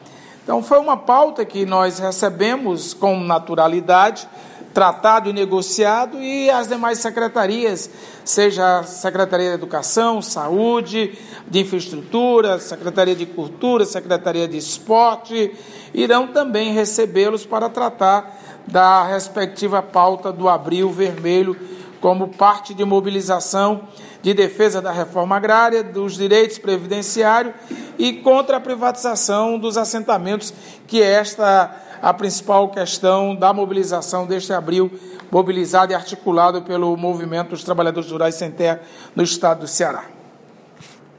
De Assis Diniz, secretário do Desenvolvimento Agrário do Governo do Ceará